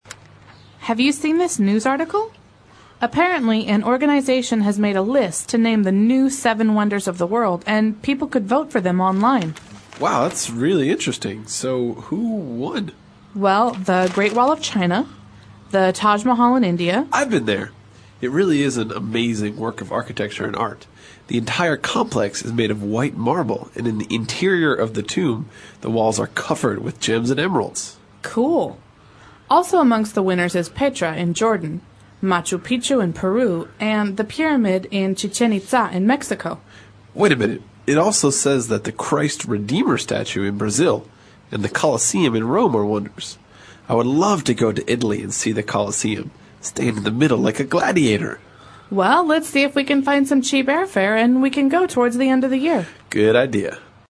外教讲解纯正地道美语|336期:世界七大奇迹 The 7 Wonders Of The World